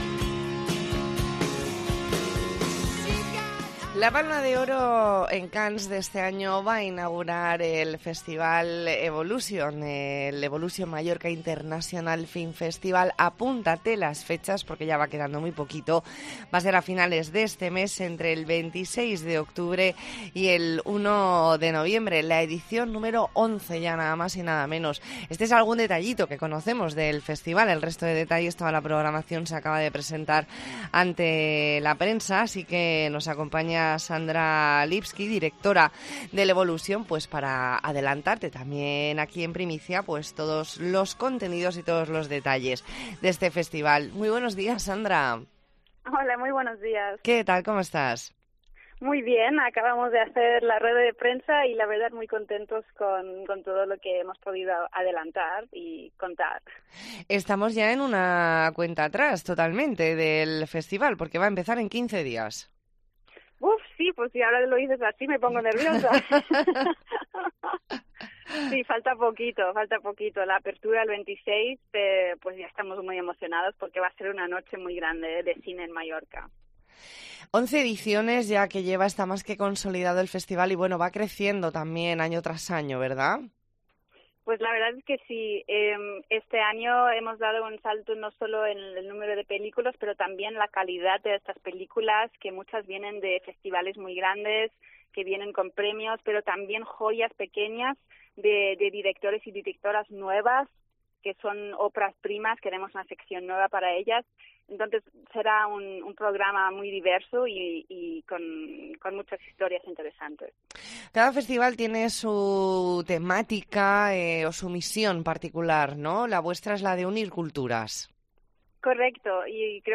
ntrevista en La Mañana en COPE Más Mallorca, martes 11 de octubre de 2022.